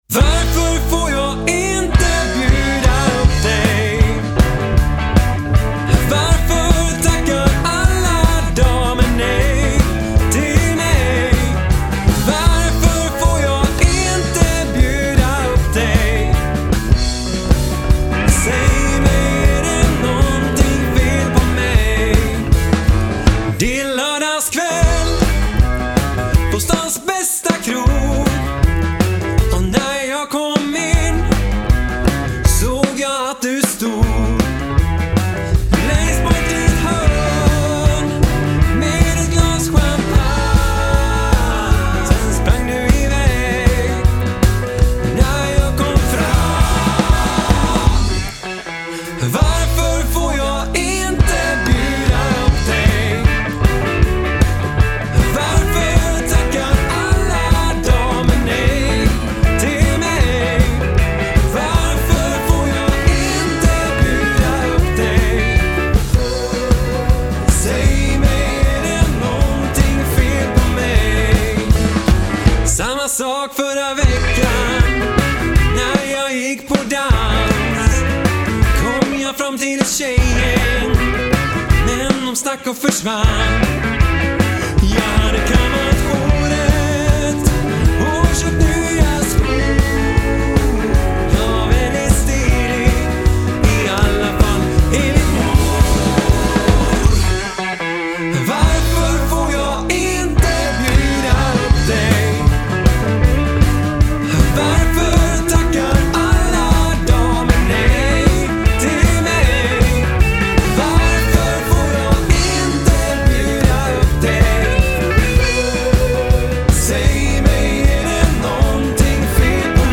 Rock
Sättningen är gitarr, trummor, kontrabas och sång.